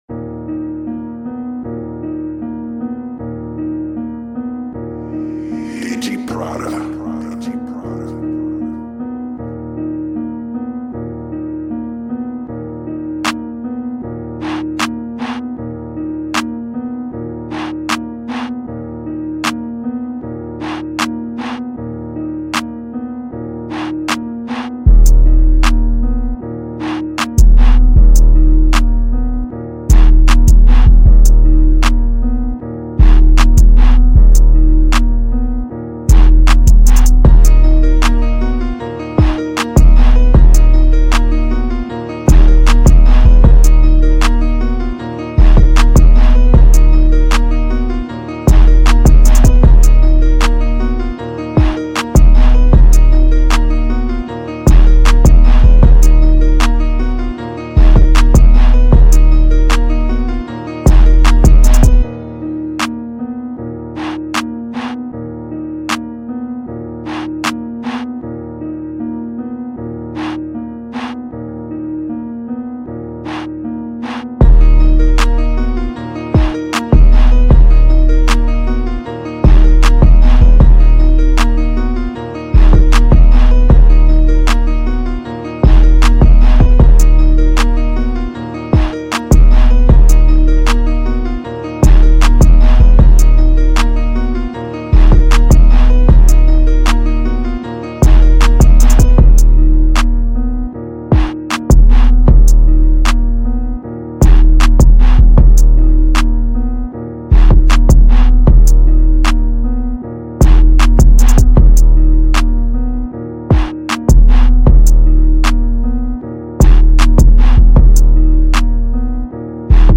2021 in Official Instrumentals , Trap Instrumentals